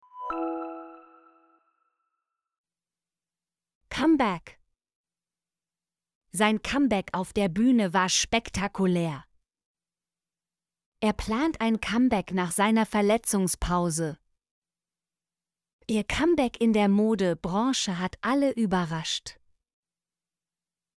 comeback - Example Sentences & Pronunciation, German Frequency List